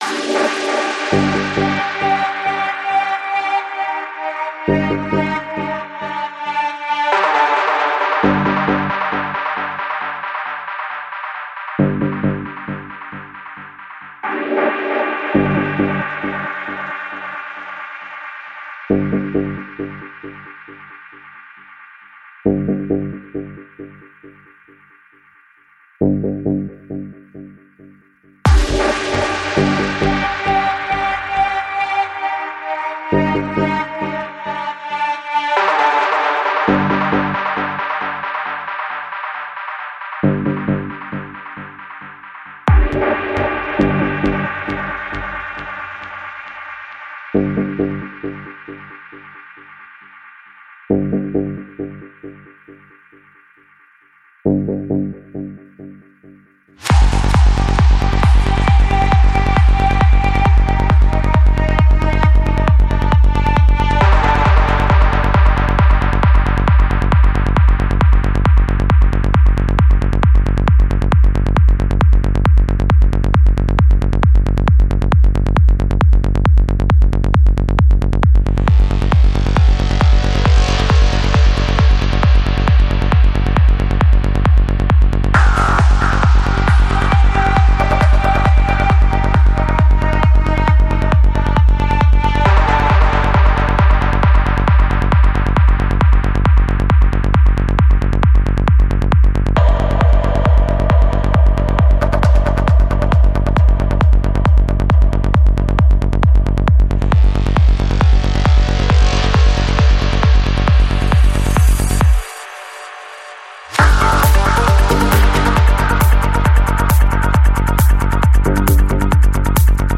Жанр: Trance
18:25 Альбом: Psy-Trance Скачать 7.23 Мб 0 0 0